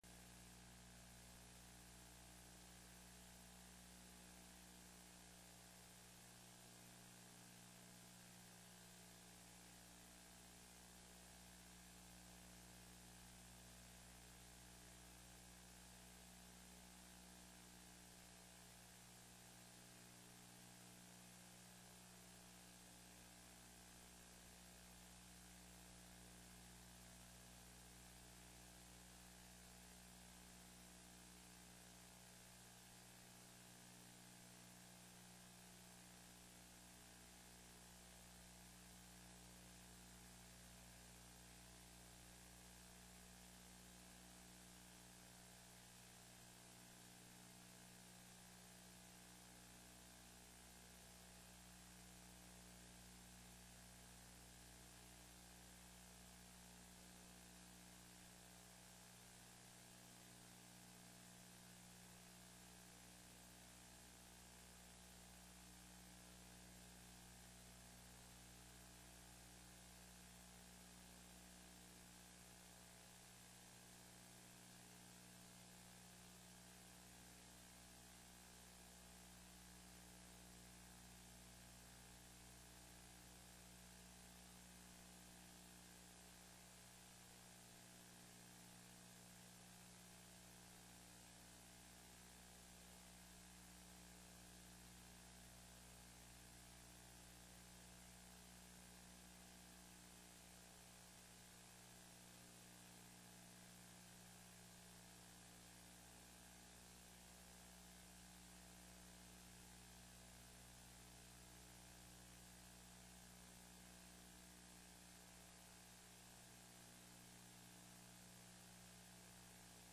Special /Public Input and General Business Meeting 11-15-11 - Nov 15, 2011